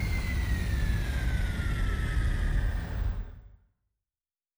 Sci-Fi Sounds / Mechanical / Engine 6 Stop.wav
Engine 6 Stop.wav